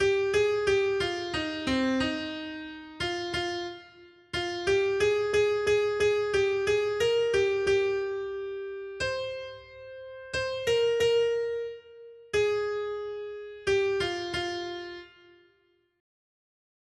Noty Štítky, zpěvníky ol610.pdf responsoriální žalm Žaltář (Olejník) 610 Skrýt akordy R: Blahoslavení chudí v duchu, neboť jejich je království nebeské. 1.